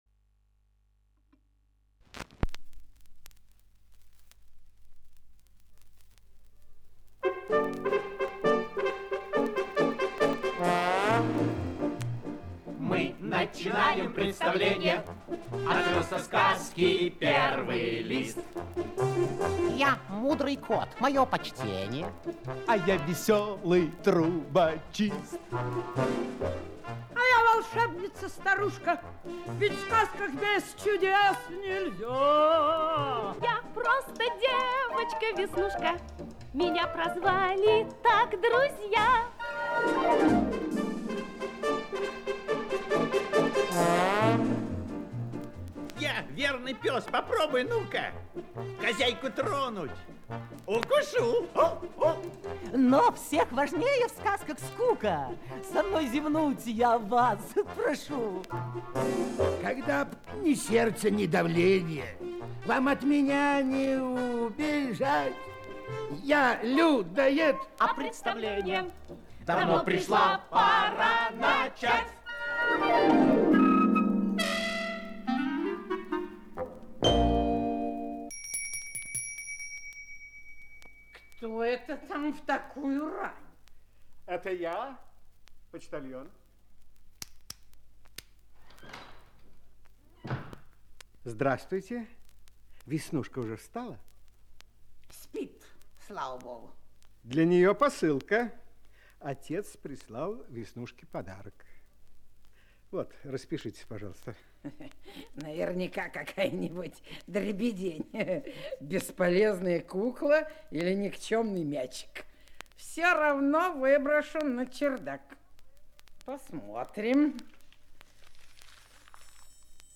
Счастливый конец - аудиосказка Борисовой - слушать